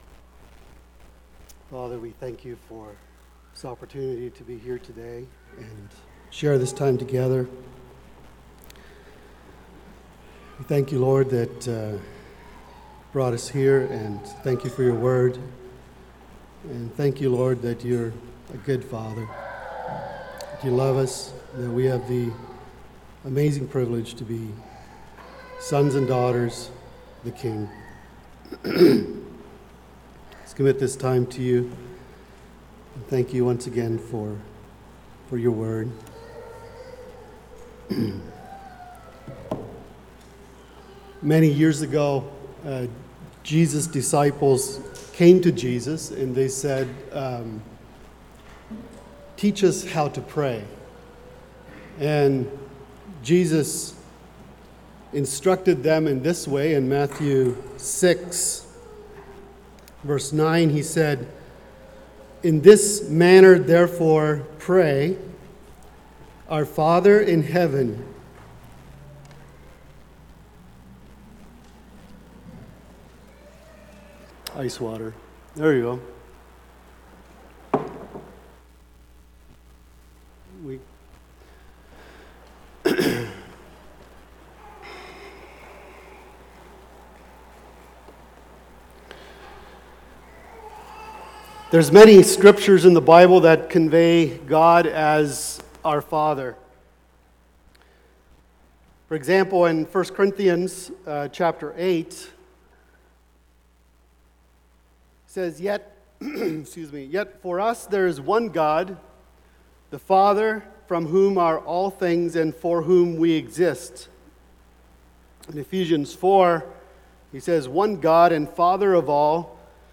Fathers Day Message